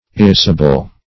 Search Result for " irrisible" : The Collaborative International Dictionary of English v.0.48: Irrisible \Ir*ris"i*ble\, a. [Pref. ir- not + risible.